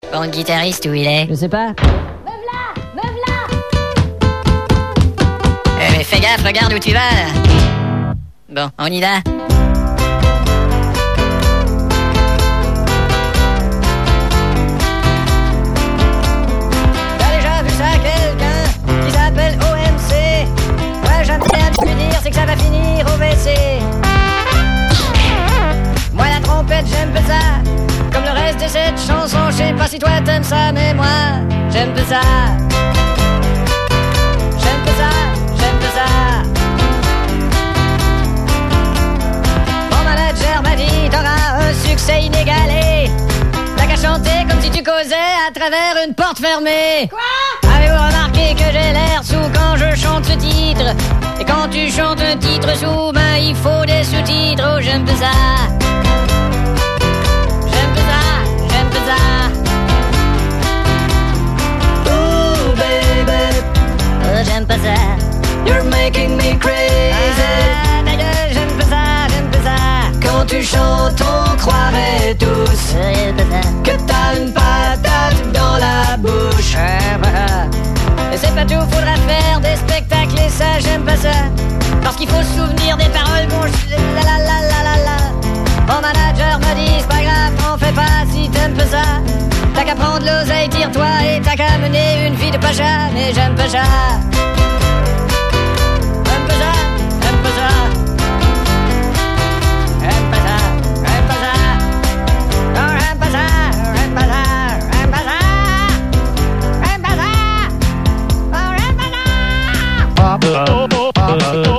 une parodie